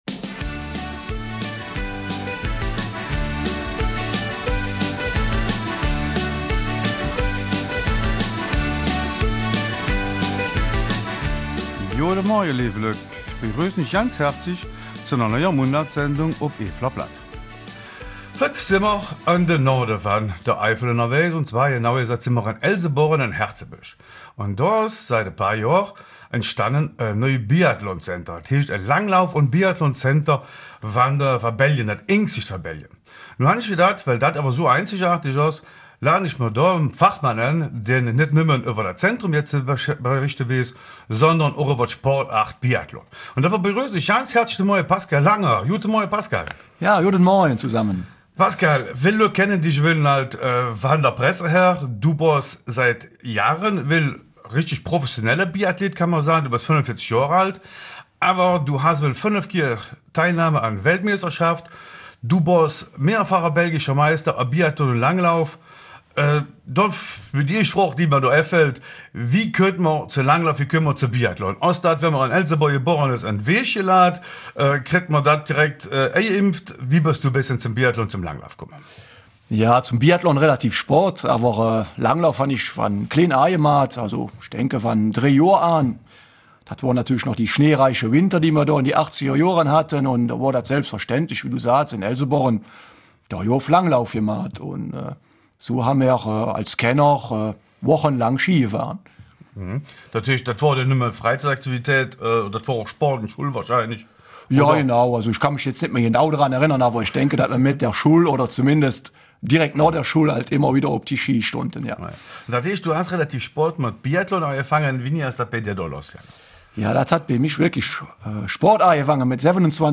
Eifeler Mundart: Biathlonzentrum Elsenborn